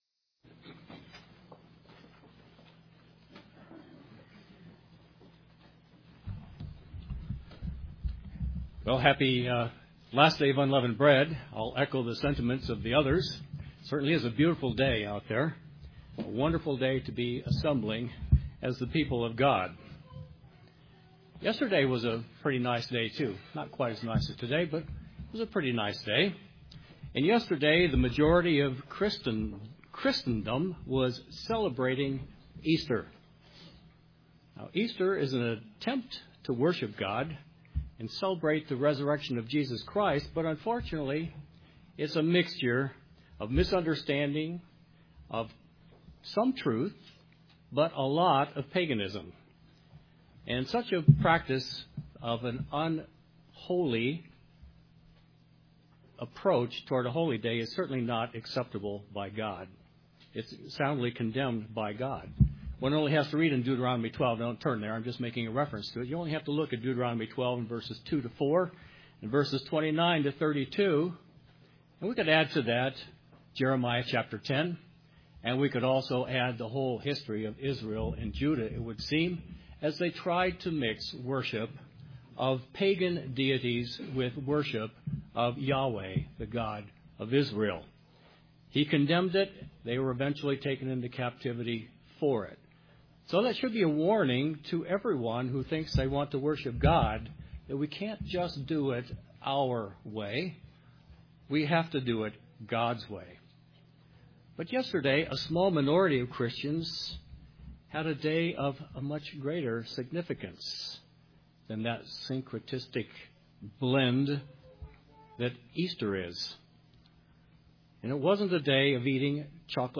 This sermon was given for the Last Day of Unleavened Bread.